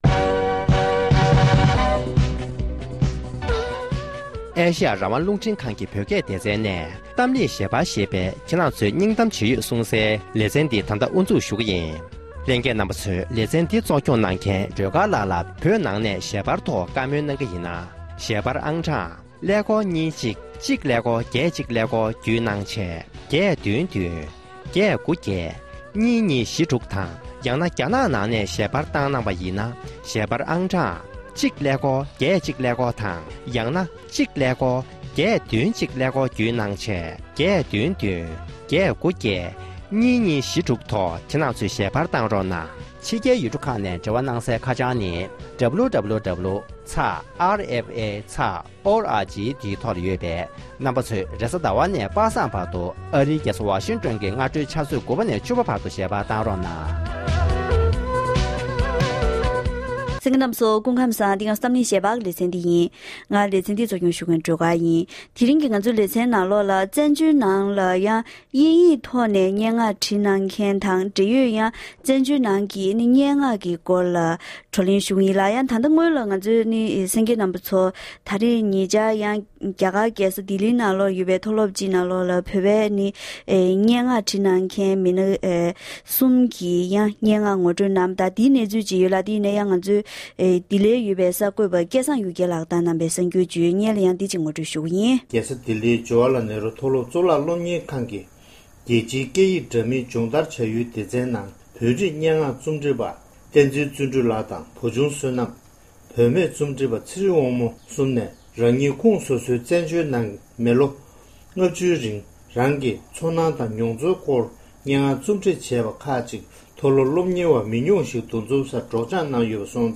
༄༅༎དེ་རིང་གི་གཏམ་གླེང་ཞལ་པར་གྱི་ལེ་ཚན་ནང་དུ་བཙན་བྱོལ་ནང་གི་བོད་པའི་སྙན་ངག་མཁན་གཉིས་དང་ལྷན་དུ